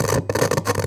pgs/Assets/Audio/Electricity_Hums/radio_tv_electronic_static_01.wav at master
radio_tv_electronic_static_01.wav